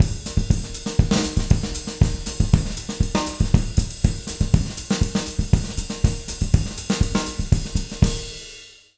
240SAMBA01-R.wav